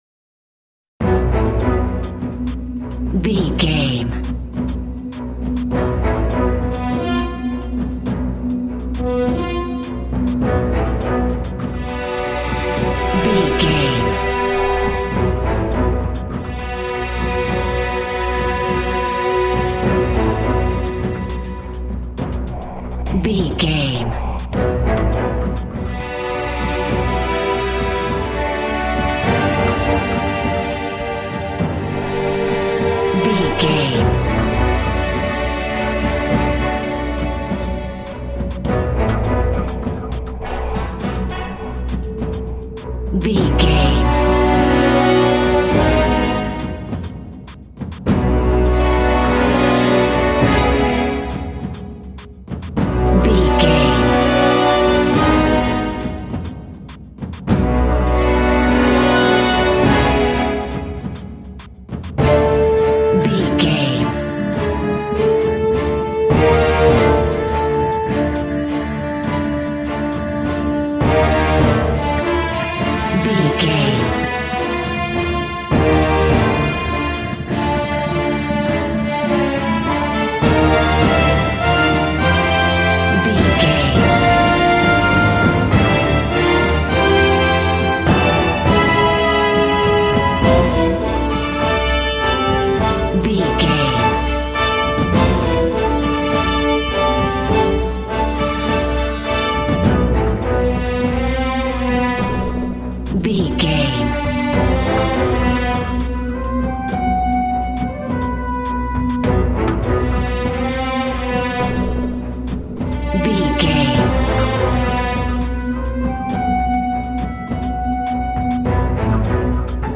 Aeolian/Minor
angry
futuristic
aggressive
orchestra
percussion
synthesiser
dark
mechanical